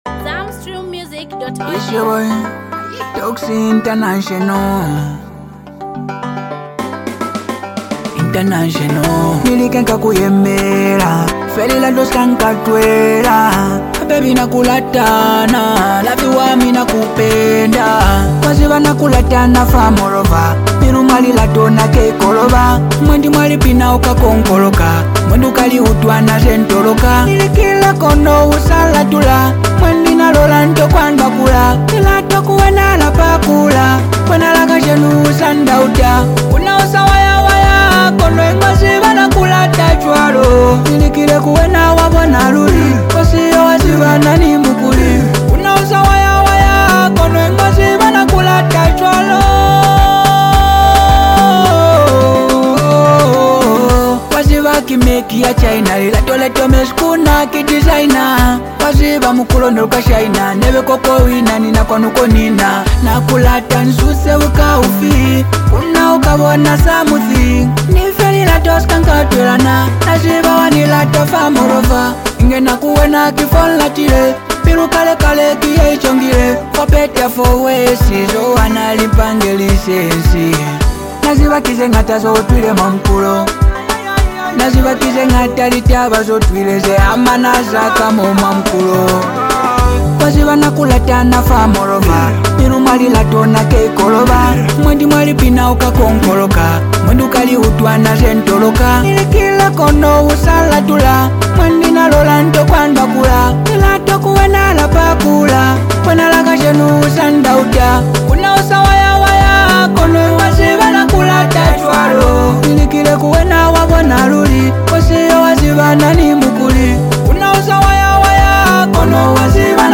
vibrate love song